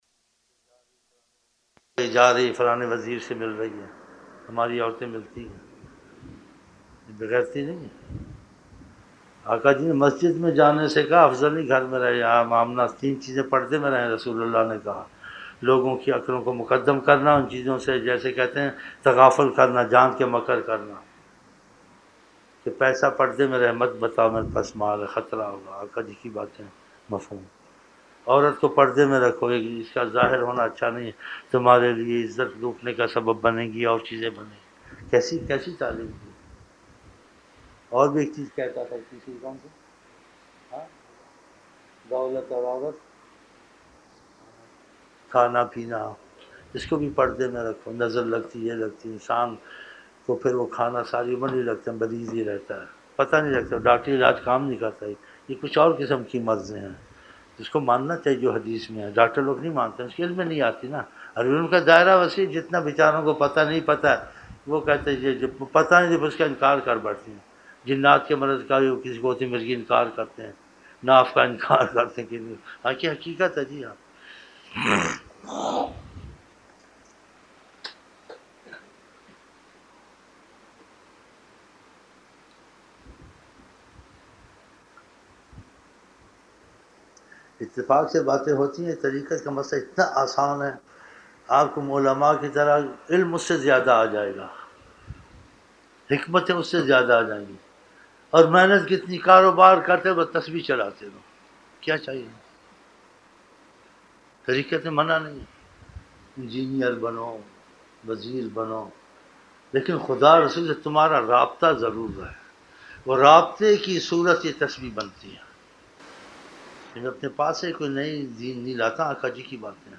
ظہر شروع کی محفل